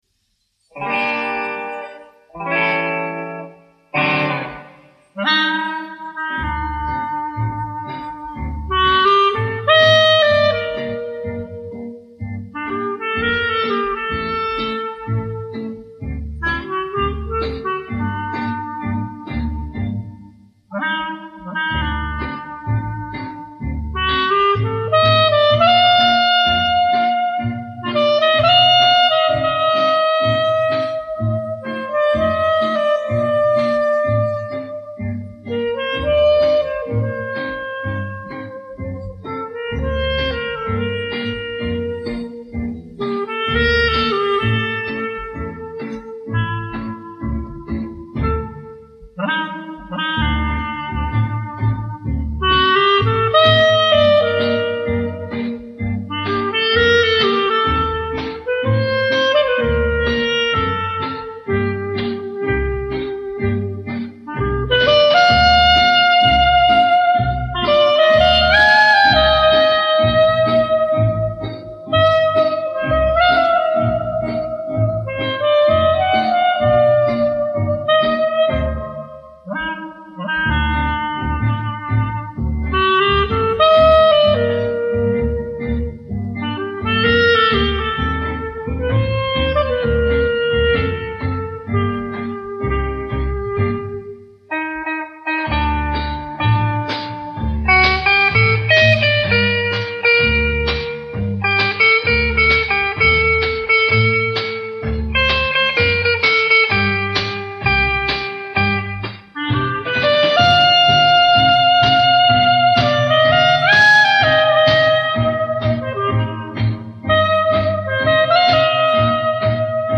Соло на кларнете